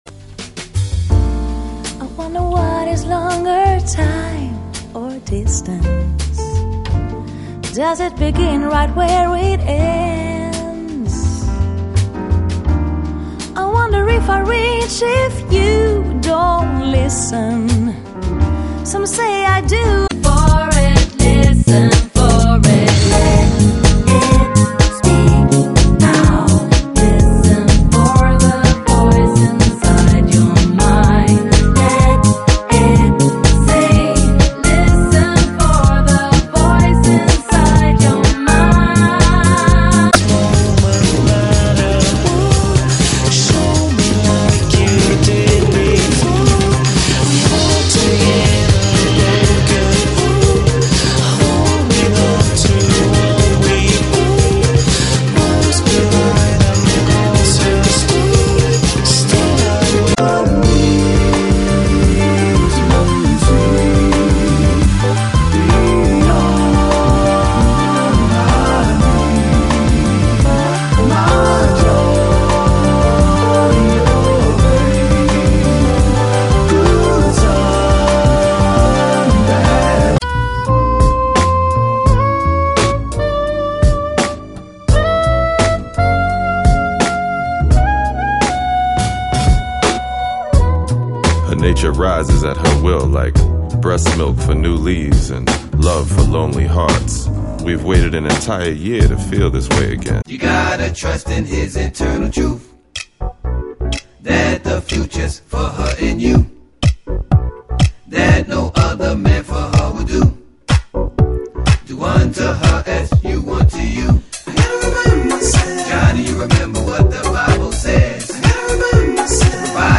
Mad piano solo.
typical jpop style.
good flow and a quite jazzy feeling.
deep, slow-moving bass and plenty of echo.
MP3 sound bite (3.2MB). 20 seconds from each song.